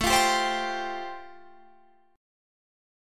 Listen to Am7#5 strummed